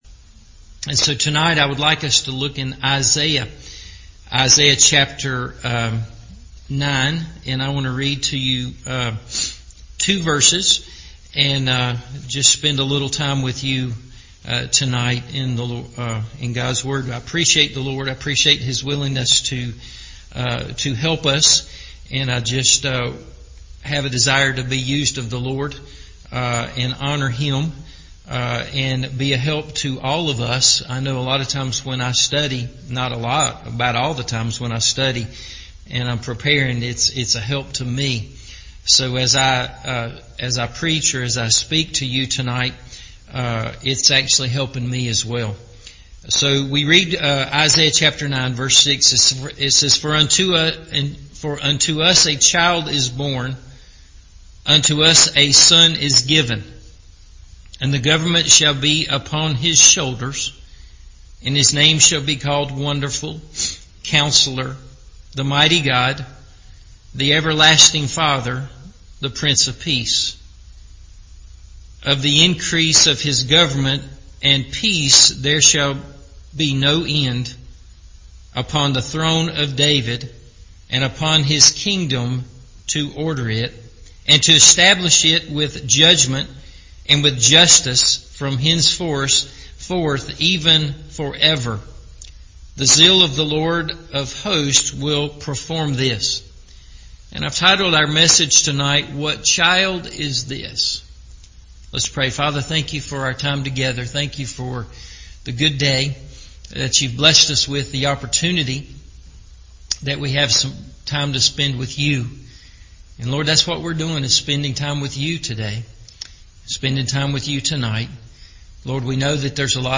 What Child Is This? – Evening Service